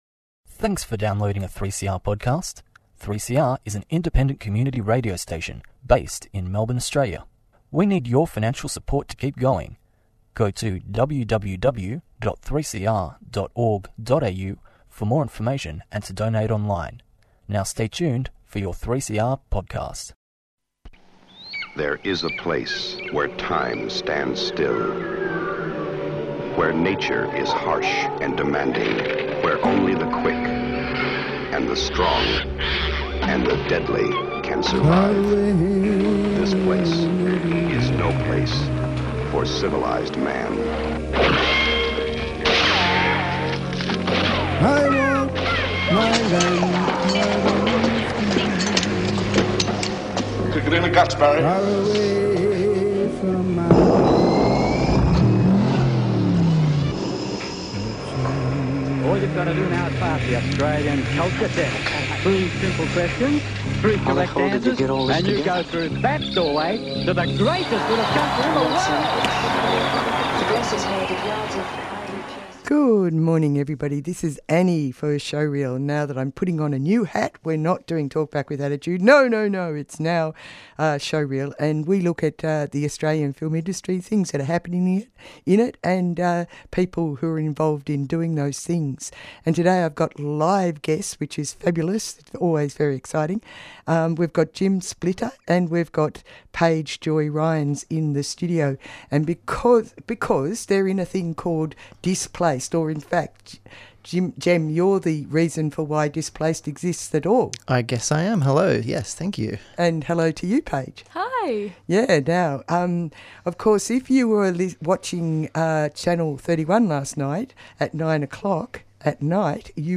Tweet Showreel Thursday 11:00am to 11:30am Your half hour of local film news, conversations with film makers and explorations into how they bring their ideas to life on screen.